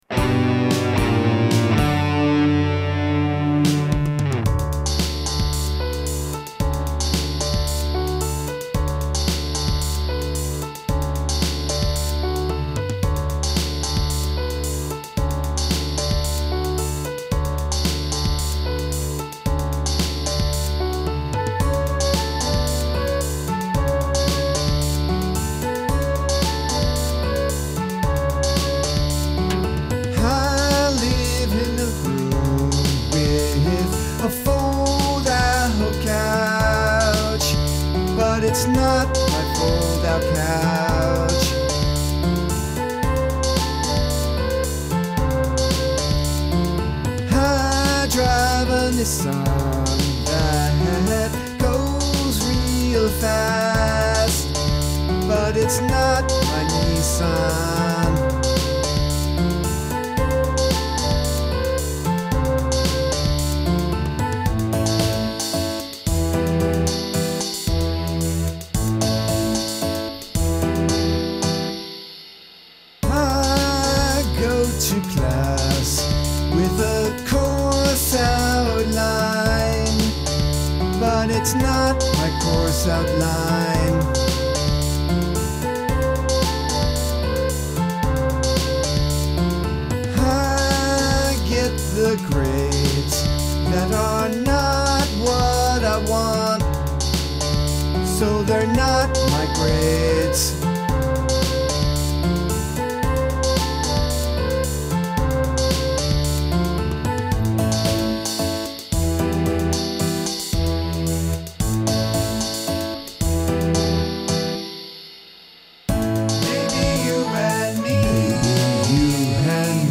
guitar synthesizer, Fender Jazz bass guitar, vocals